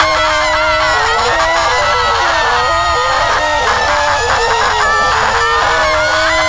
Sound was an important part of the game so the next day we spent recording audio such as screams, whispers and general voice lines to give the monsters as all three were unique in their own way.
A woman screaming for help - Will you be the good Samaritan who will save her? A croaky rasping voice telling you it's a trap - Will you listen?
A normal voice trying to confuse you - makes you feel disorientated.
The sounds when put together sound amazing and does a great job at making you feel insecure and confused.
It's very threatening and disturbing and really makes you feel uneasy when playing.